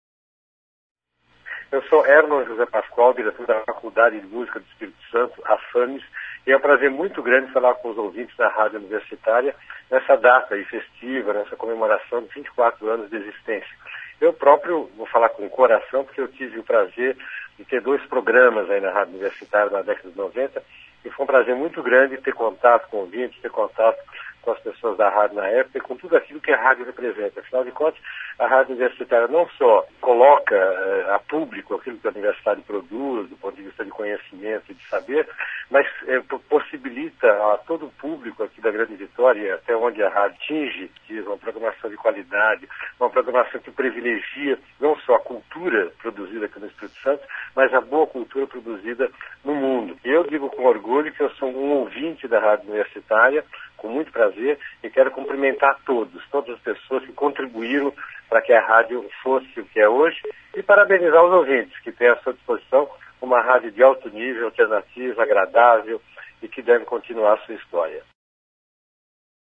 Confira os depoimentos: